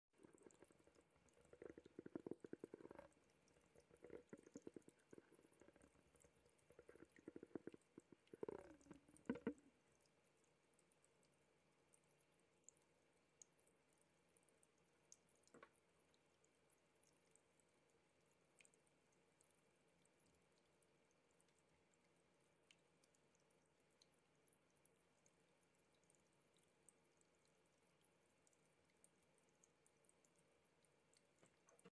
🚀✨ Today, we’re diving into zero-gravity tingles with space sounds, tapping, and whispering from the cosmos!